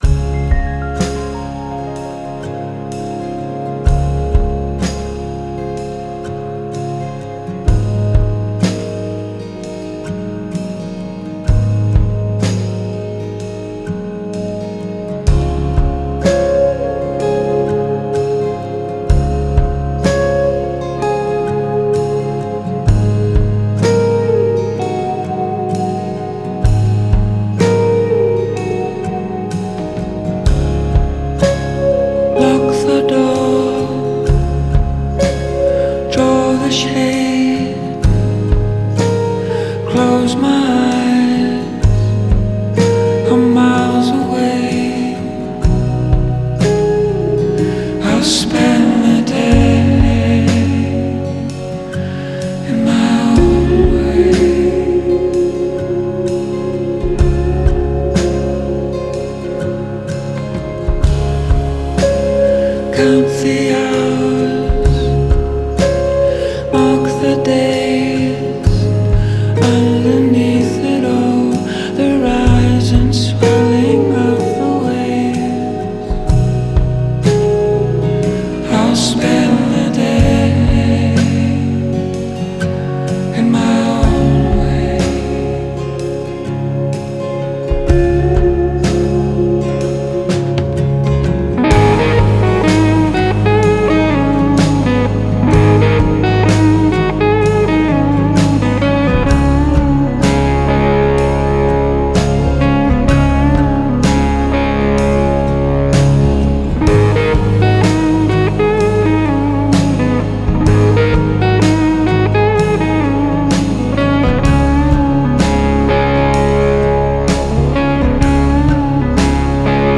Genre: Folk Rock, Singer-Songwriter